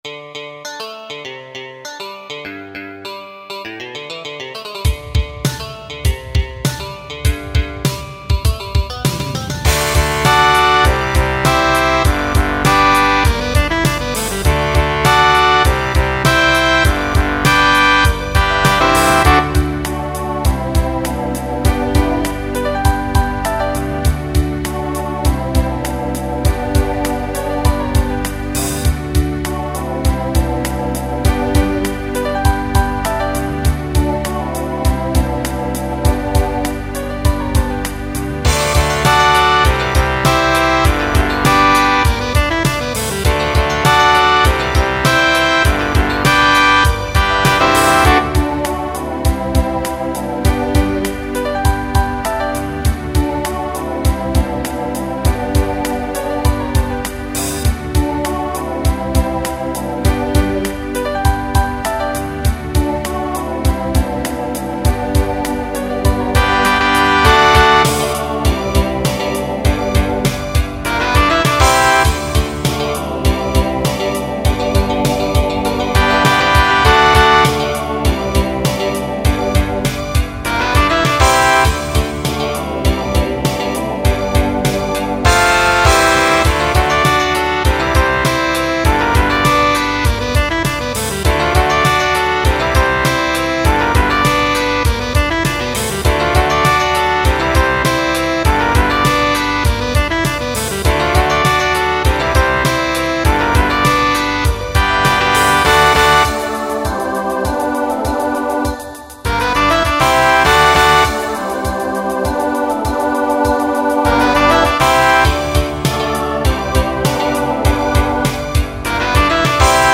Ends SATB.
Voicing TTB Instrumental combo Genre Country